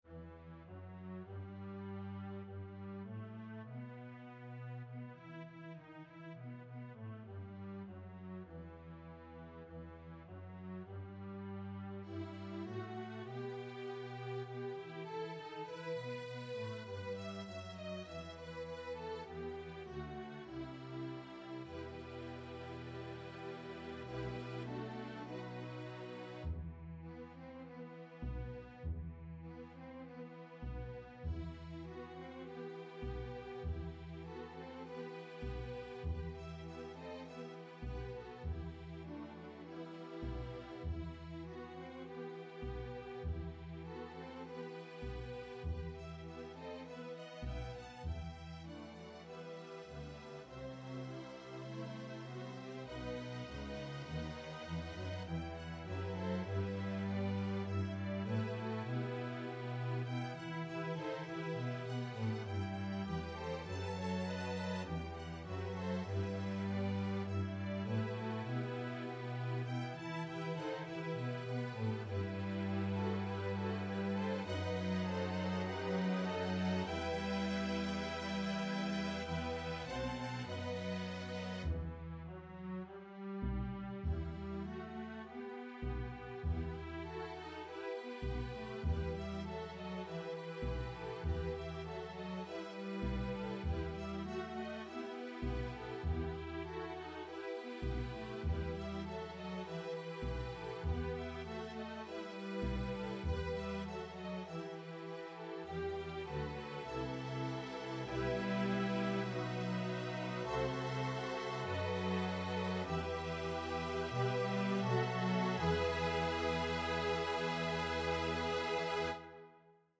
Folk and World